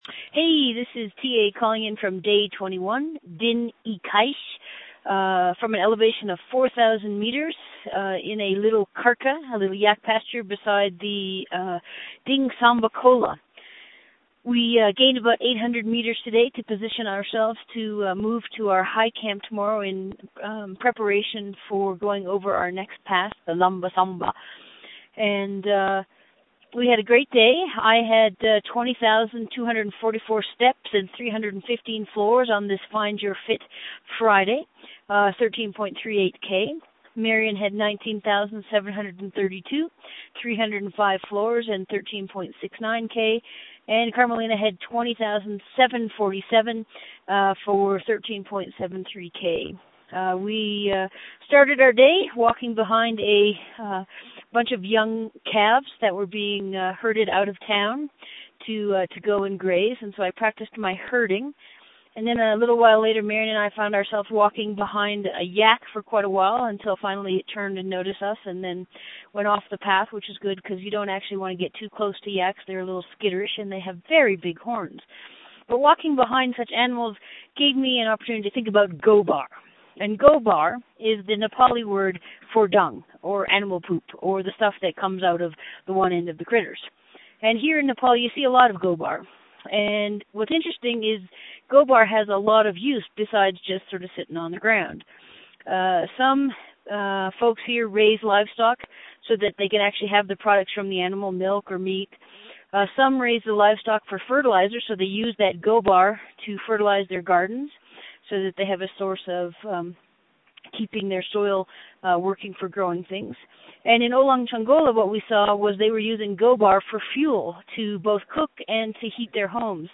Great Big Walk acknowledges the support of the Memorial University of Newfoundland Quick Start Fund for Public Engagement in making these updates from the field possible.